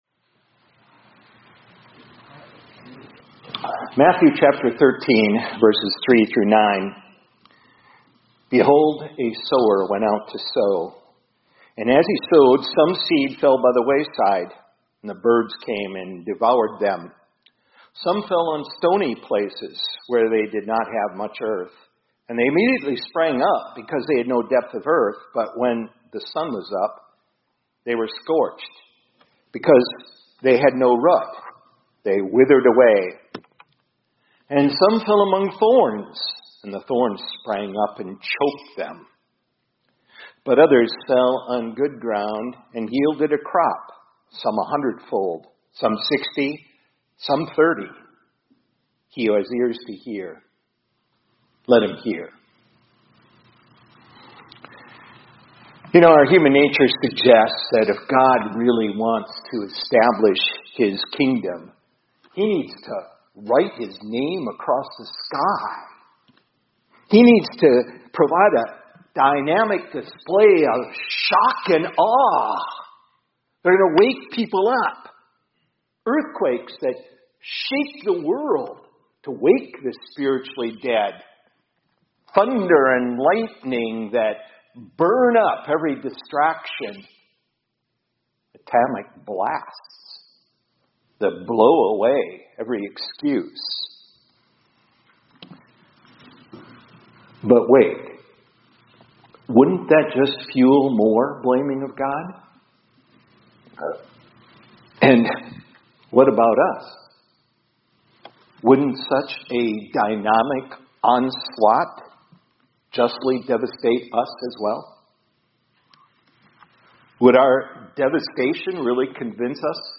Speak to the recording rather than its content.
2025-05-07 ILC Chapel — Trust God for the Seed…